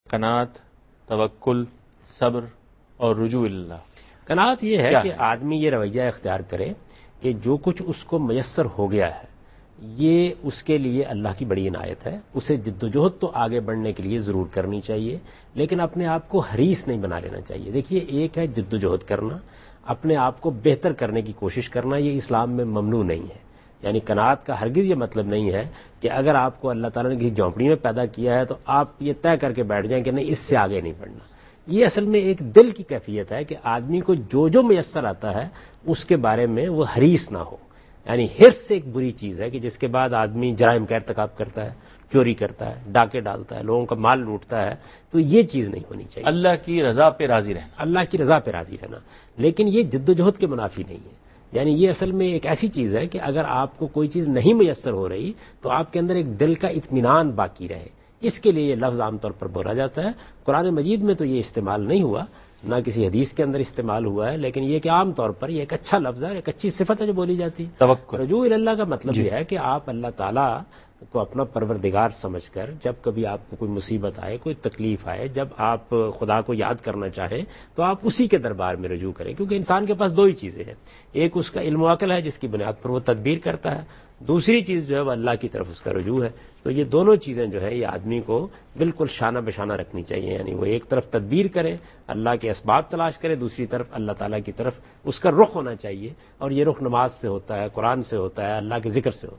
Category: TV Programs / Dunya News / Deen-o-Daanish /
Javed Ahmad Ghamidi addresses this question in program Deen o Daanish on Dunya News.